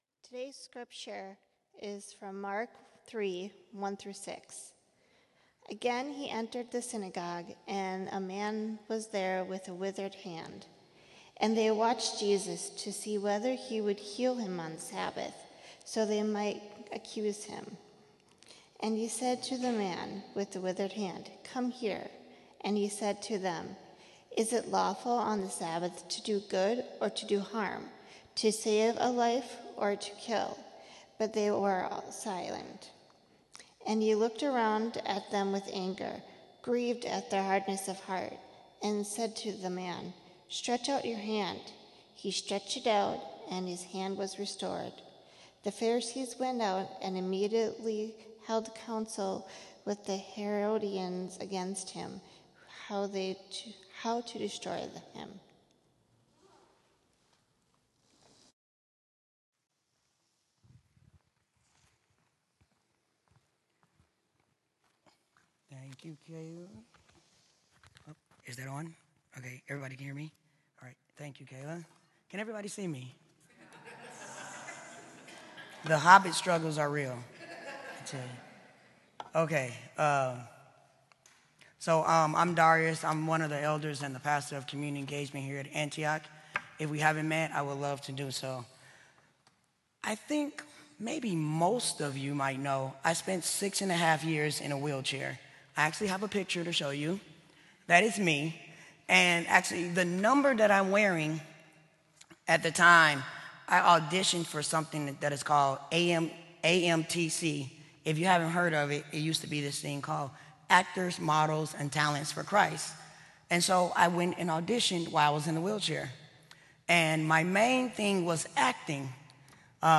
Sermon: Mark: Is it Lawful?